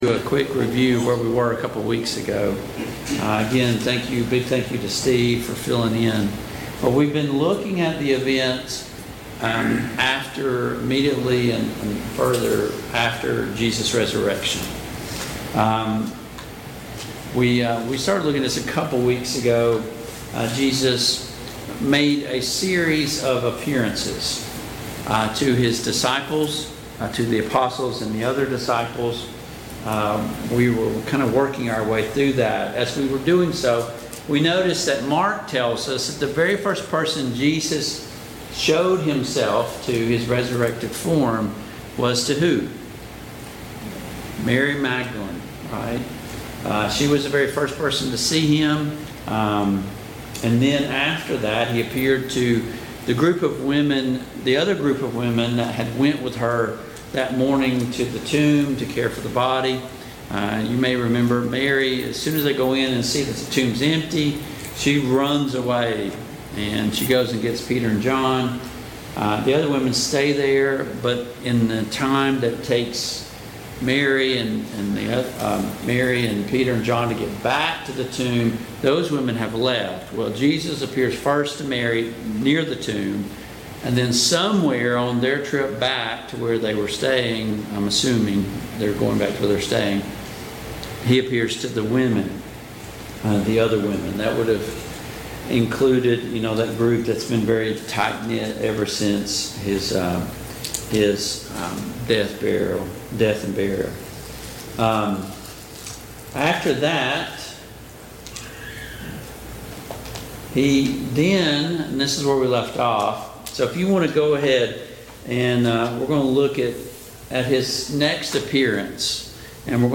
Service Type: Mid-Week Bible Study Download Files Notes Topics: The Resurrection of Jesus Christ « 8.